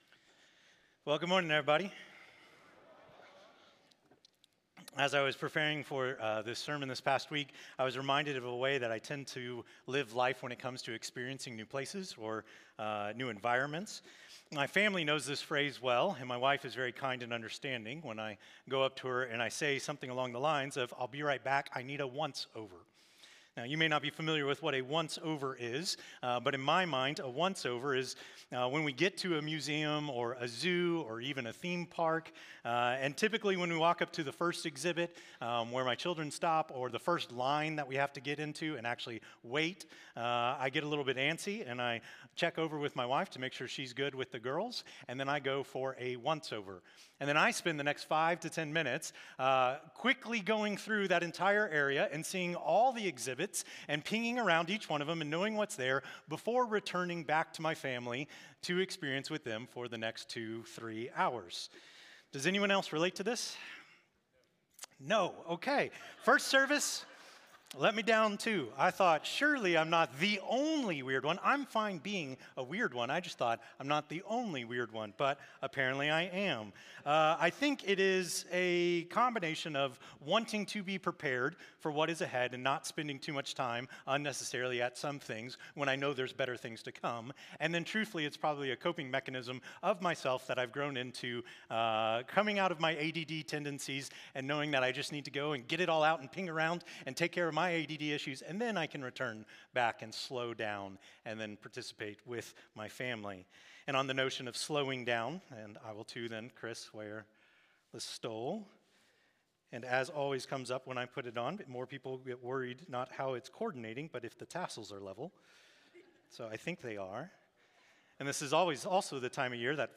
by South Spring Media | Dec 14, 2025 | 2025 Sermons, Advent 2025 | 0 comments